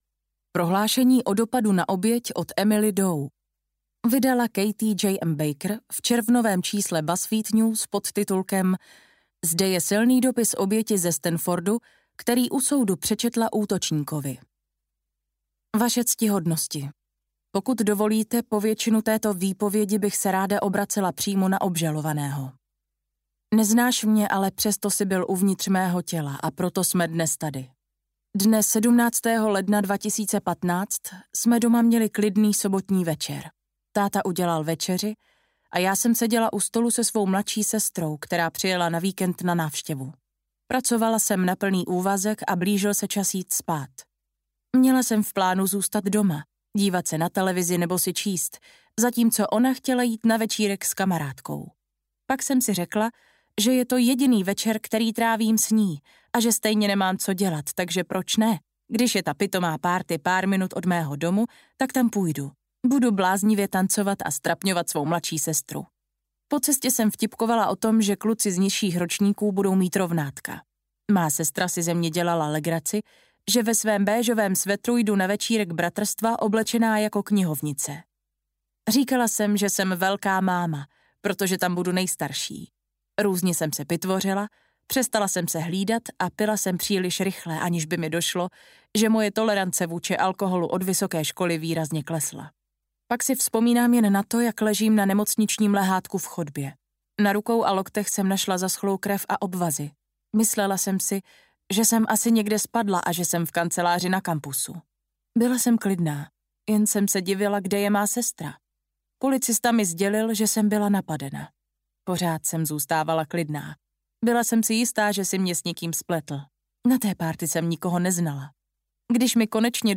Mám jméno audiokniha
Ukázka z knihy